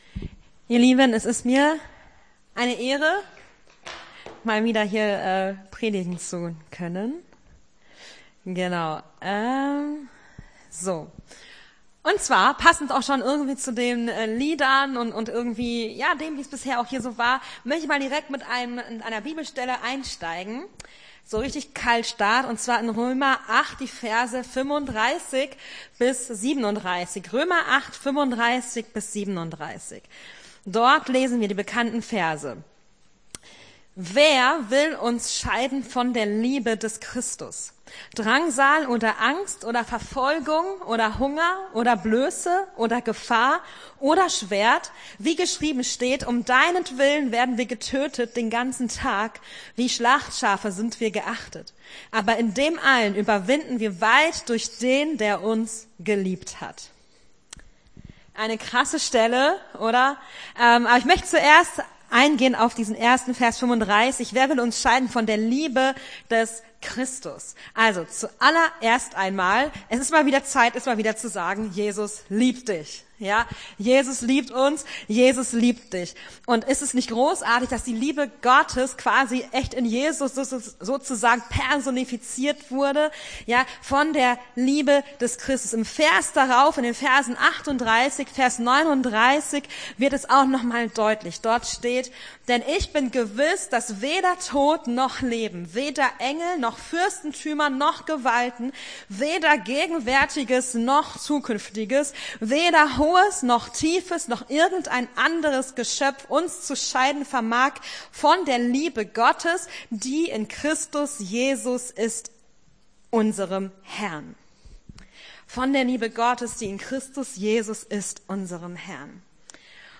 Gottesdienst 13.08.23 - FCG Hagen